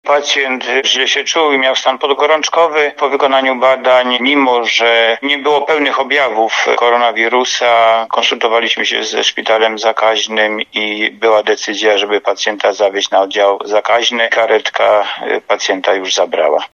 Mówi lekarz ze Szpitalnego Oddziału Ratunkowego w Tarnobrzegu